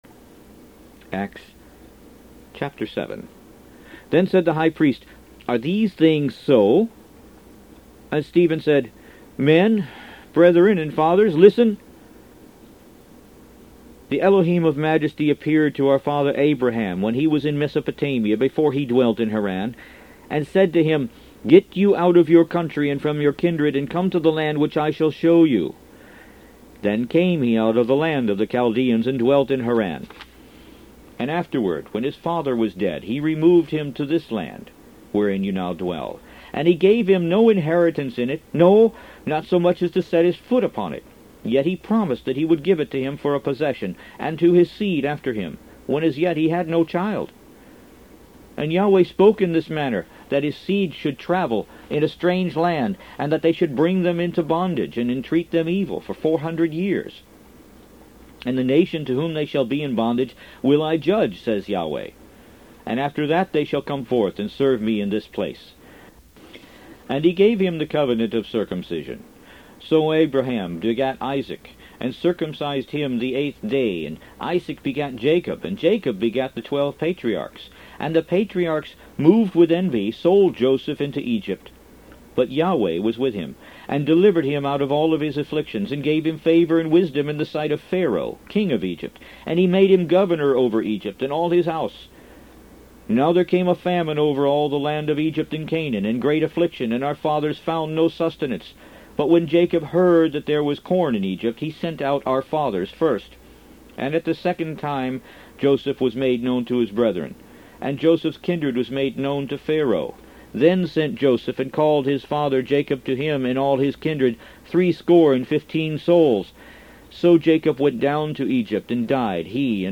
Root > BOOKS > Biblical (Books) > Audio Bibles > Messianic Bible - Audiobook > 05 The Book Of Acts